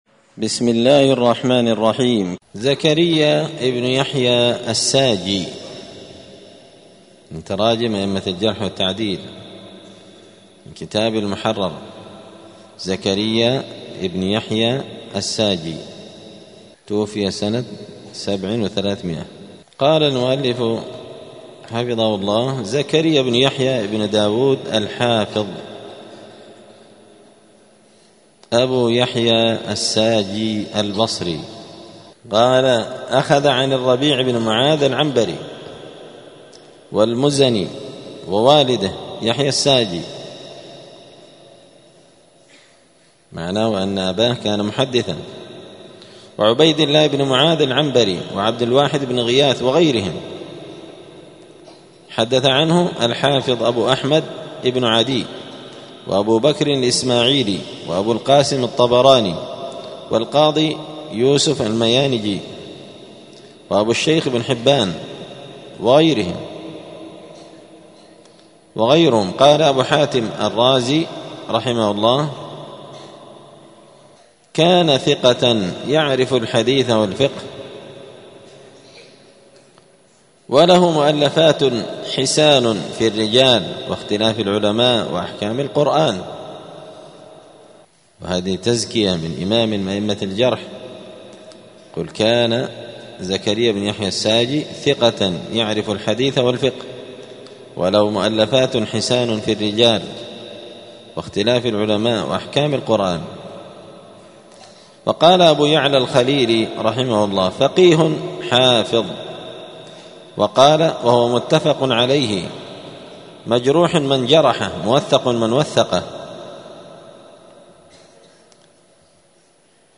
*الدرس الثامن والتسعون (98) باب التعريف بالنقاد زكريا بن يحيى الساجي*